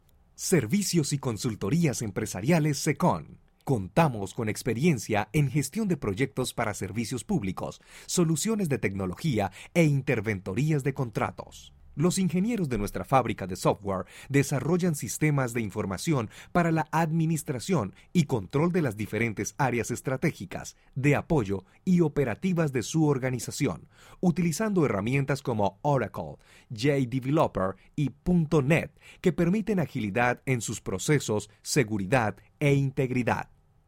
Una voz cálida y profesional
kolumbianisch
Sprechprobe: eLearning (Muttersprache):